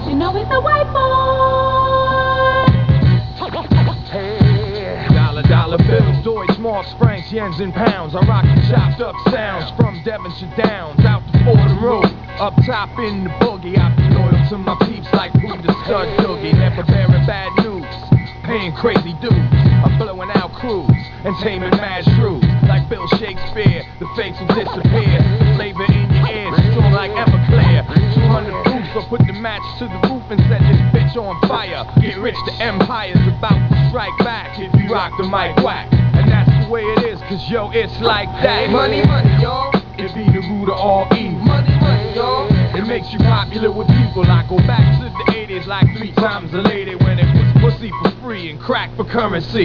It is alot of acoustic guitar